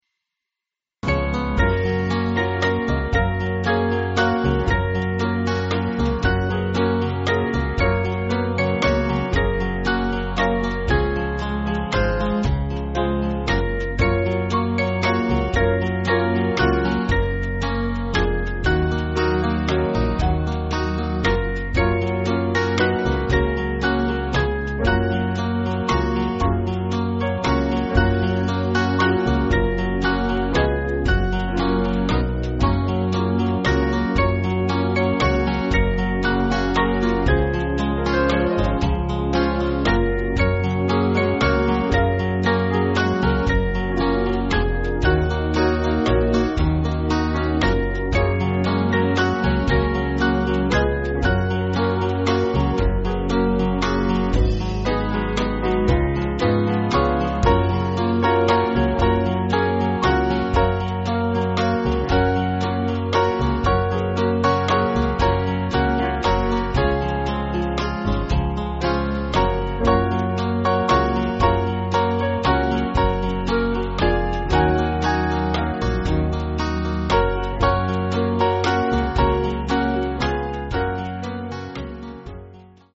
Small Band
(CM)   3/Gb-G